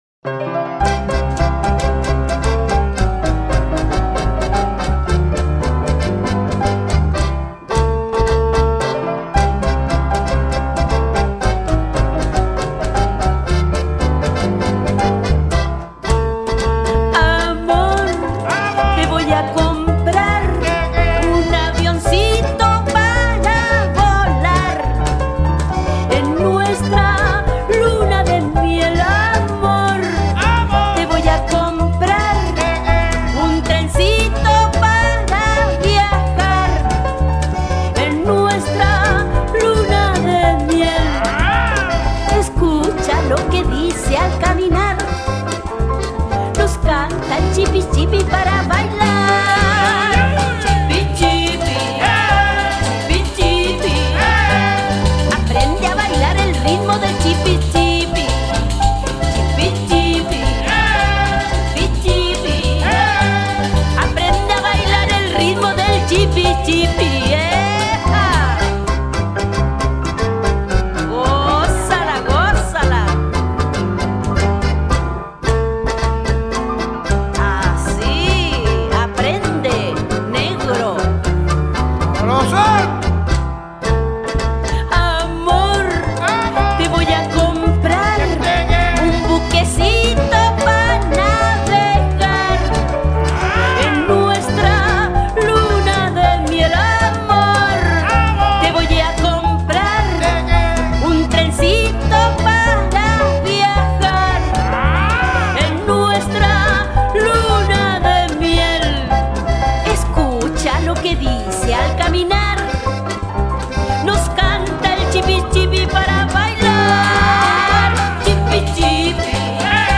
多么轻松愉悦而又浪漫的曲子呀！